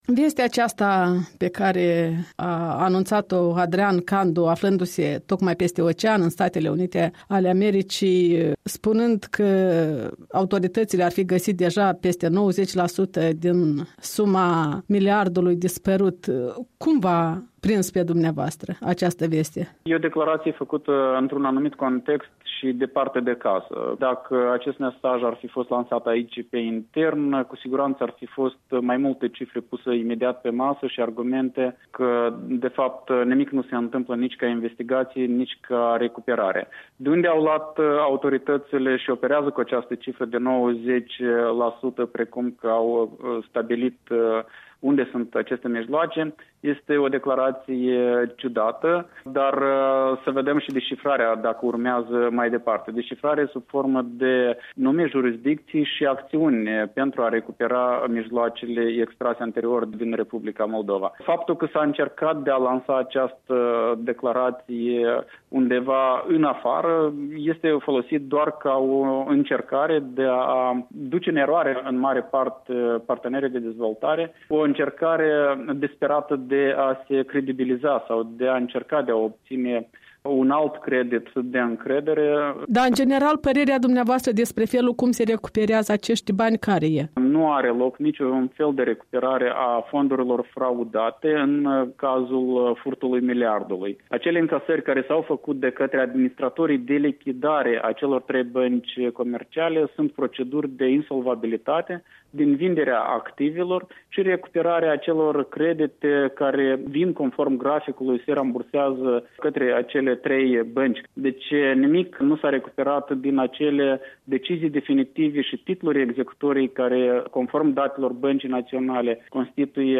Interviu cu expertul Transparency International, fost ministru al finanţelor.